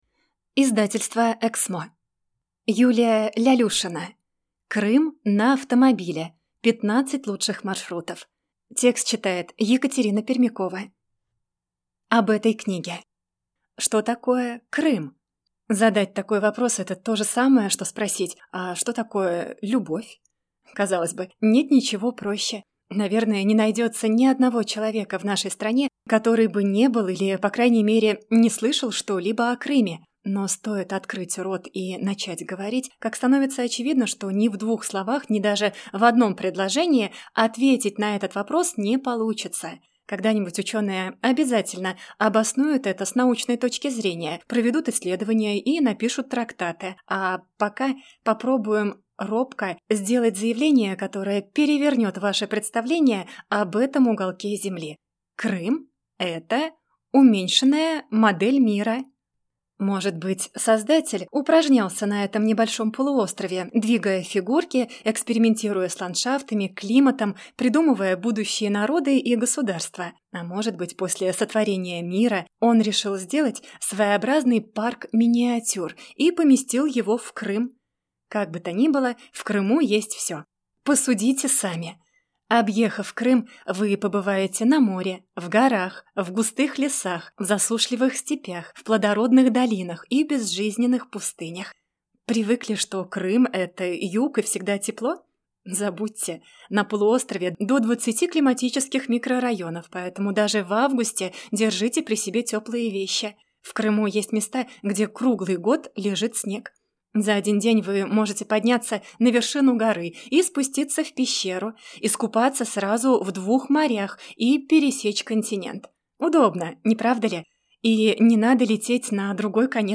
Аудиокнига Крым на автомобиле. 15 лучших маршрутов | Библиотека аудиокниг